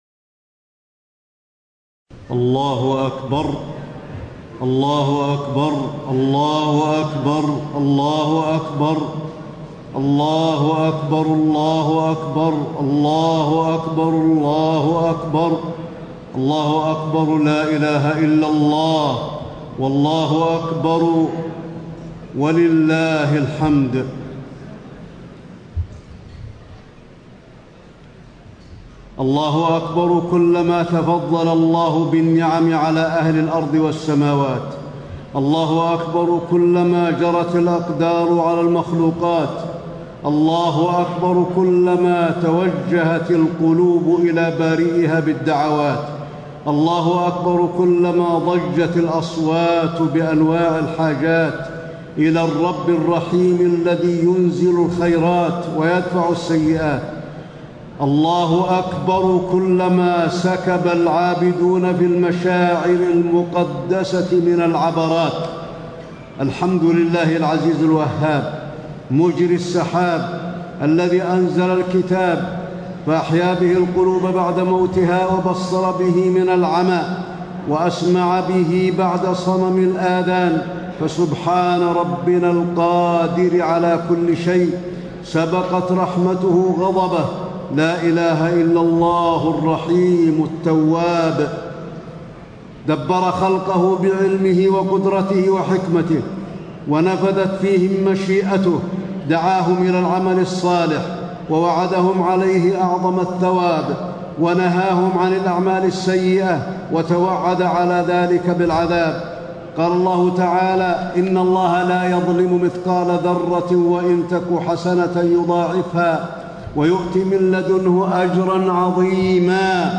خطبة عيد الأضحى - المدينة - الشيخ علي الحذيفي
المكان: المسجد النبوي